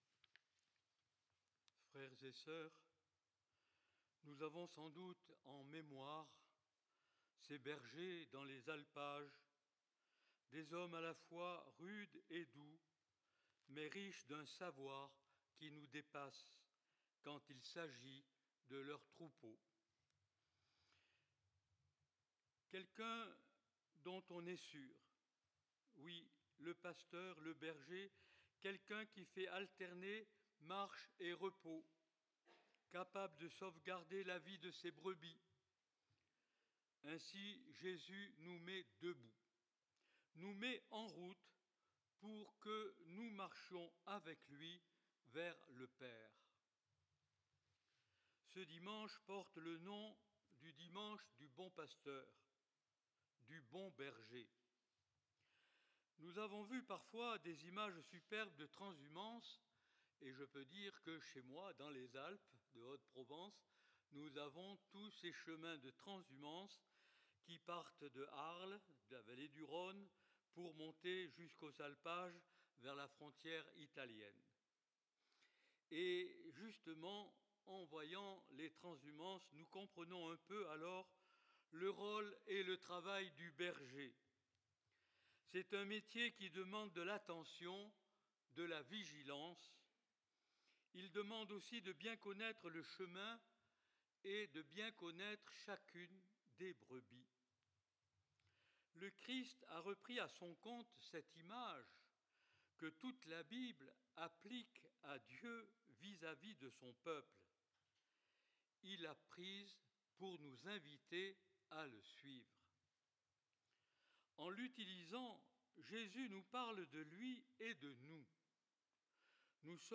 (Nos excuses : l'enregistrement n'est pas très fort, mais on peut l'entendre avec des écouteurs si l'on monte le volume à fond.)